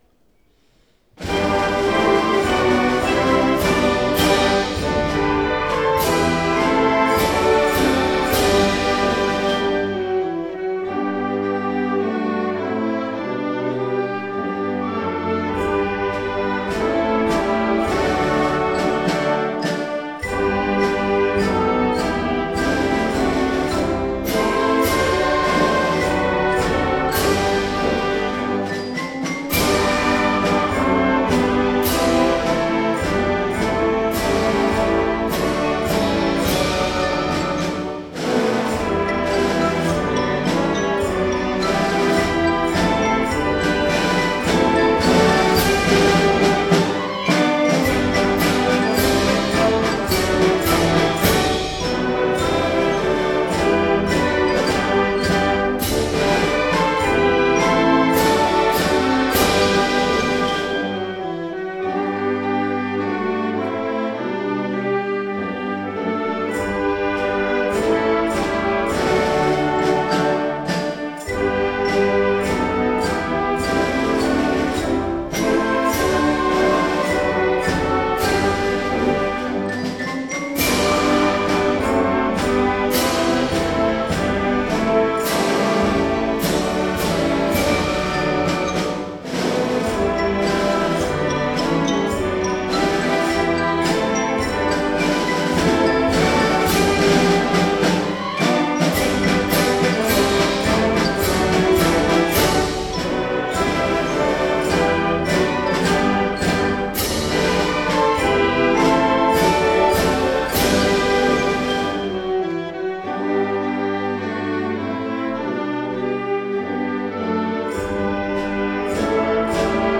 四日市農芸高校創立80周年記念行事
たくさんのOBと全校生徒と一緒です。